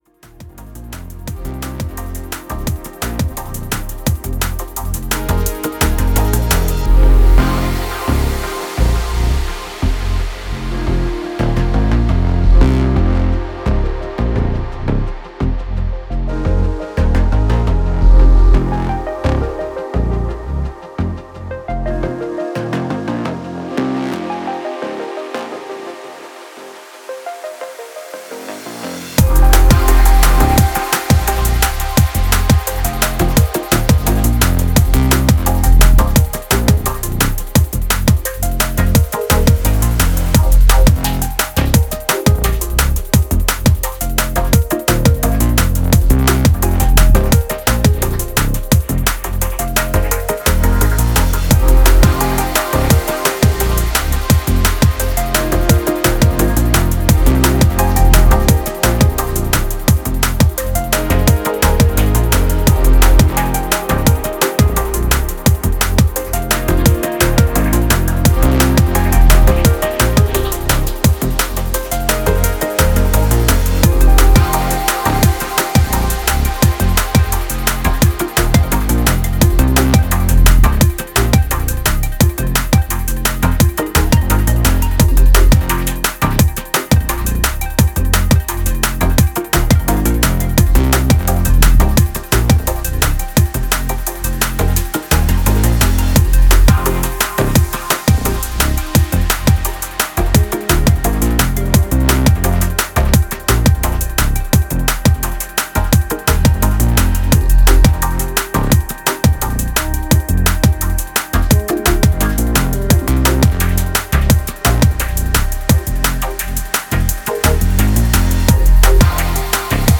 drum and bass
electronic music